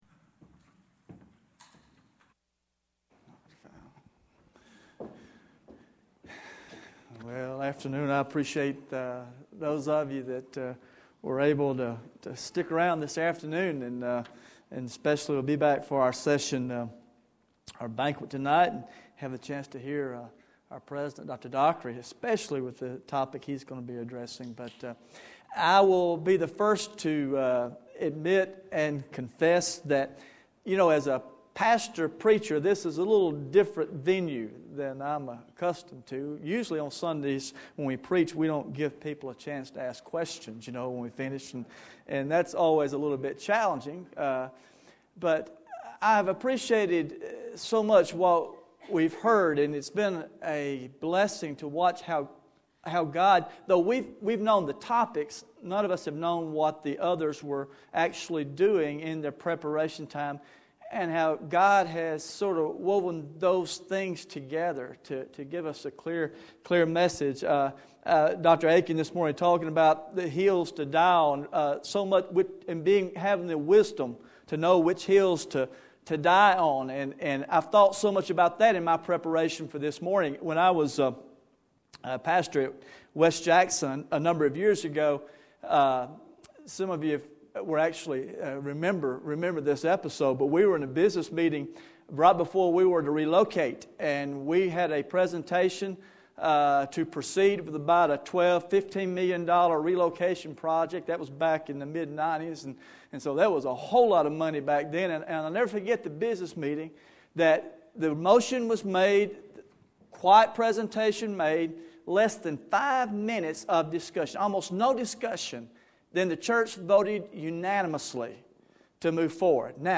Future of Denominationalism Conference